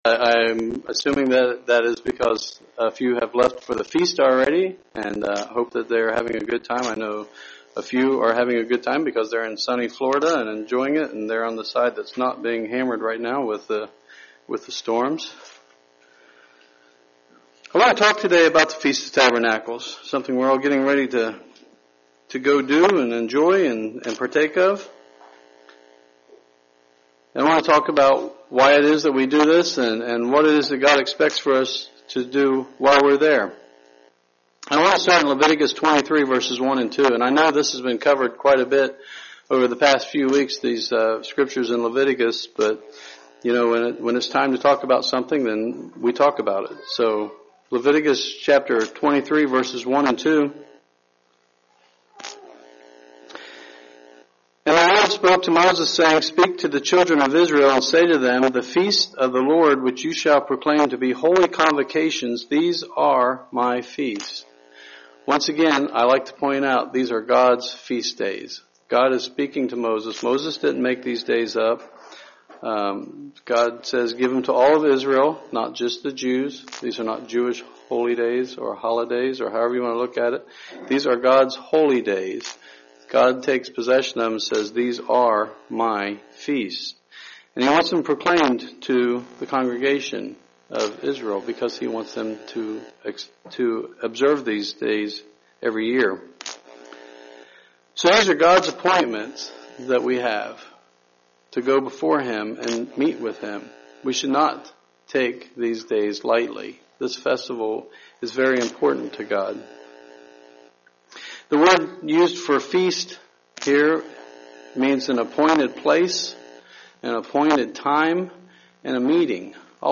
The Feast of Tabernacles is quickly approaching. This sermon explains why we keep the Feast and also what we are to do during the feast.